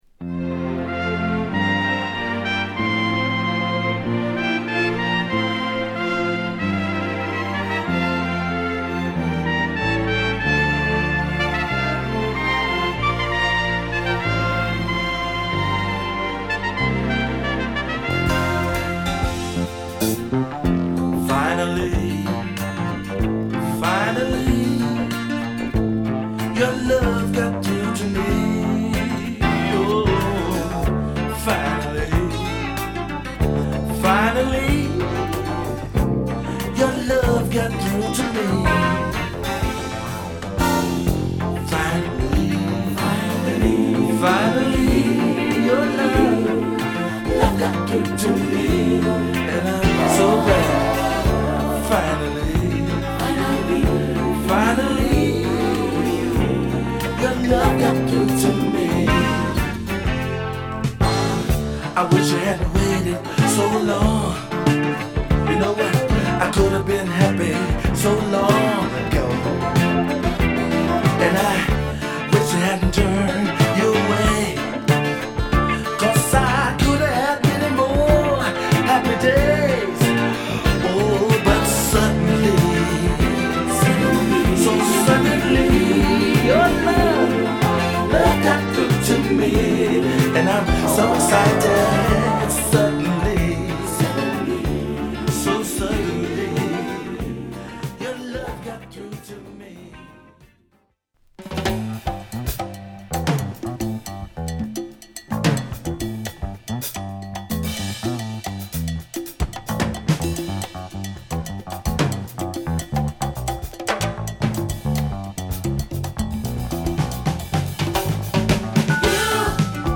カッティングギターやエレピ等を絡めた靭やかなミディアムソウルA2
太いベースとパーカッシブなリズムにエレピを絡めた軽快なラテン風味のメロウダンサーB3